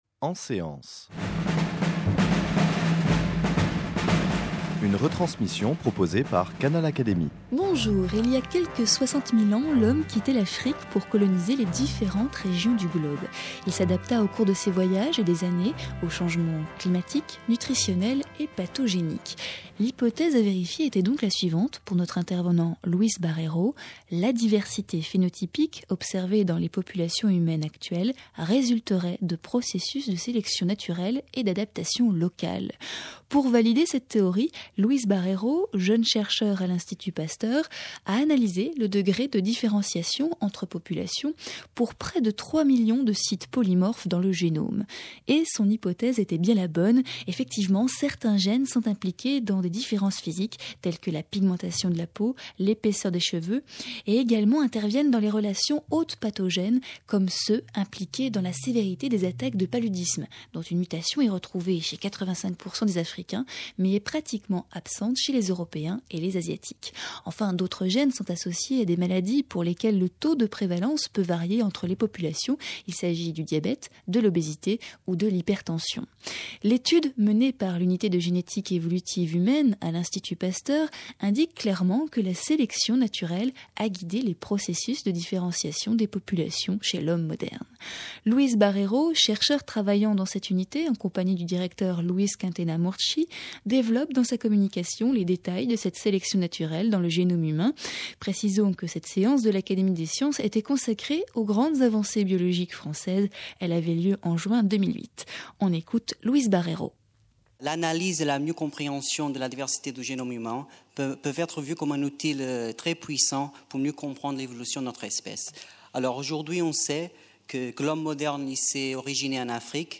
lnvité à l’Académie des sciences, il évoque ses recherches au cours d’une séance consacrée aux grandes avancées françaises en biologie.